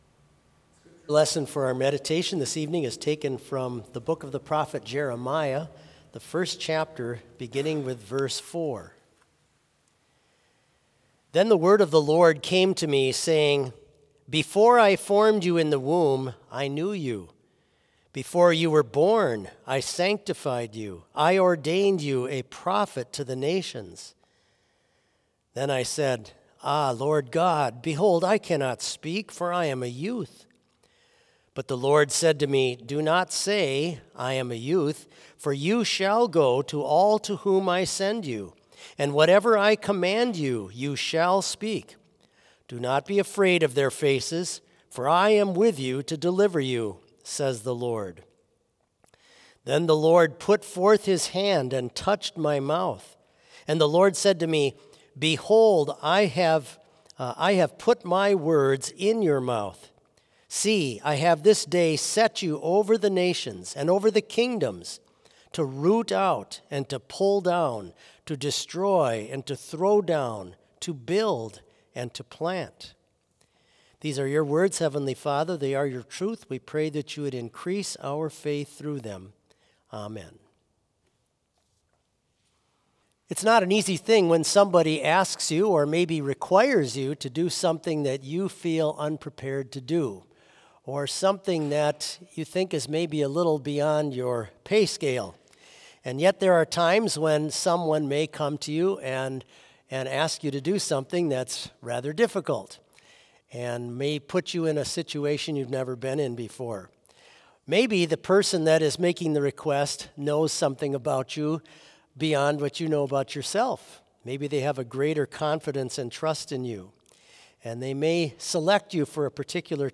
Vespers worship service held on February 26
BLC Trinity Chapel, Mankato, Minnesota
Complete service audio for Vespers - Wednesday, February 26, 2025